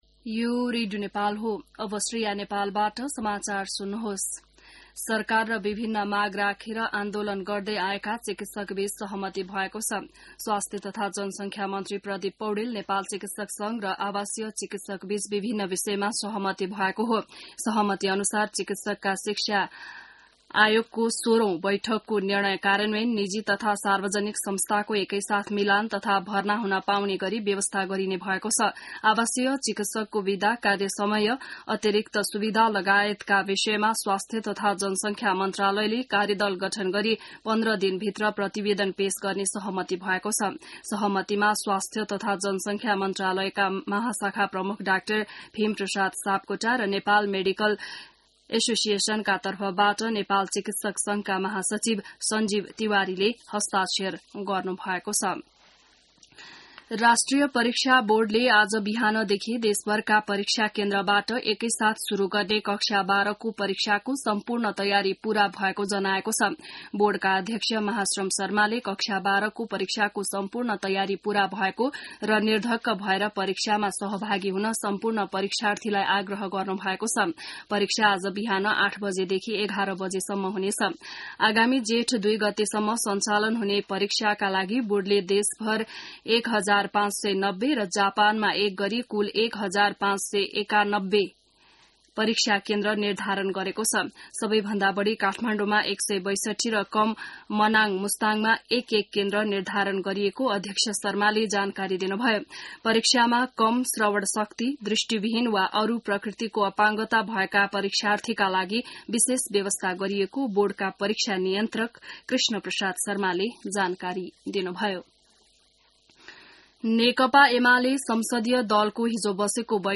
An online outlet of Nepal's national radio broadcaster
बिहान ६ बजेको नेपाली समाचार : २१ वैशाख , २०८२